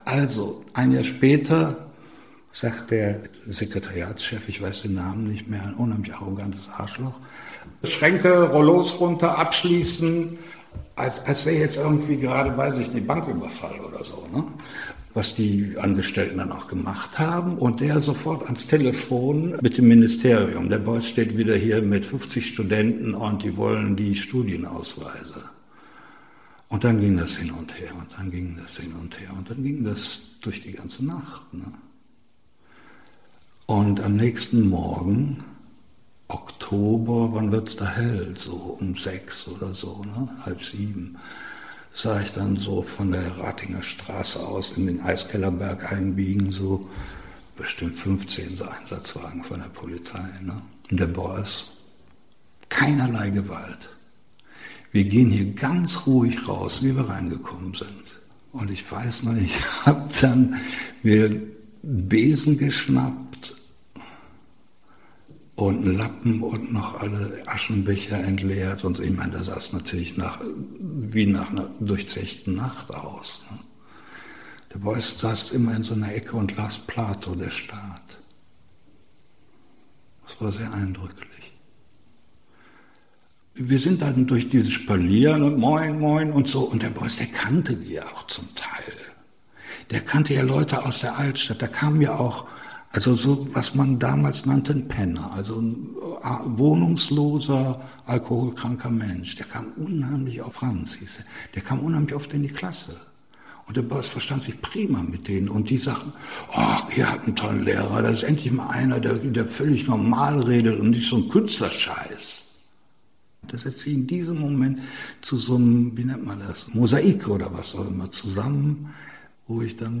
Interview Audioarchiv Kunst: Walter Dahn über die Lehre in der Klasse von Joseph Beuys